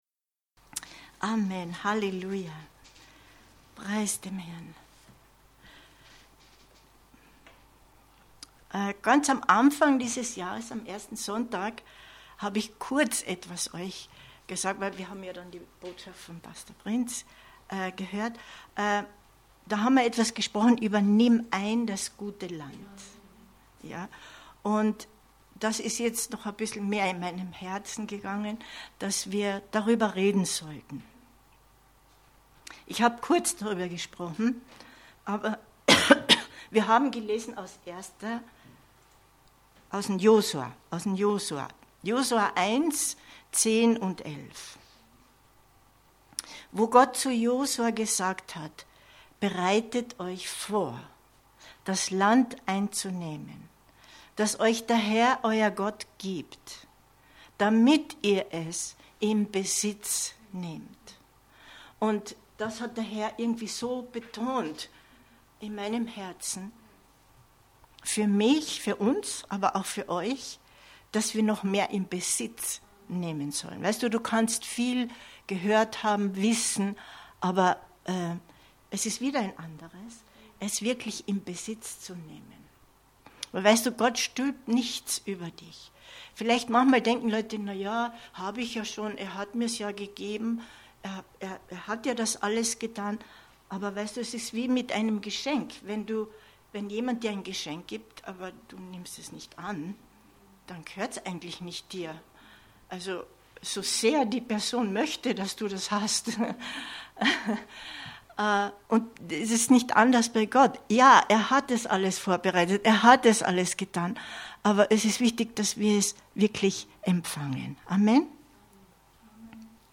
Geistliche Realitäten in Besitz nehmen 23.01.2022 Predigt herunterladen